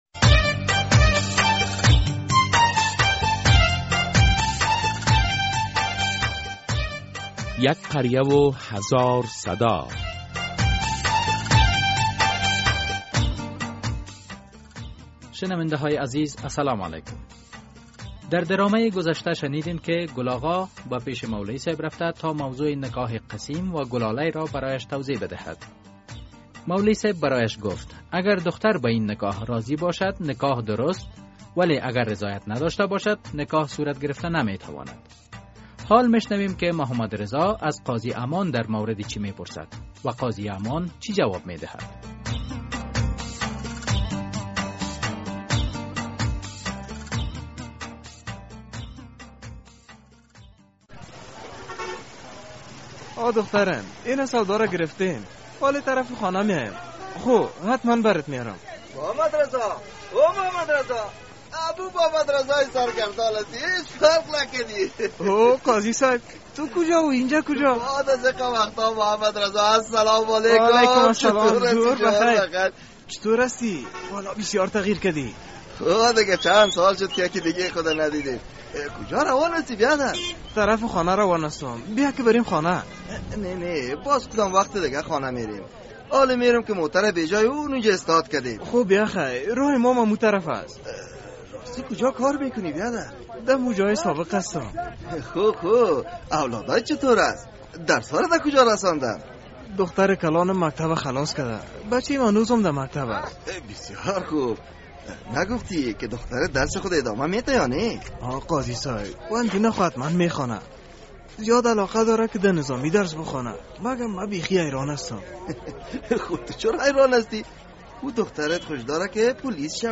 در این بخش درامه به زنان قهرمان در تاریخ اسلام اشاره شده است.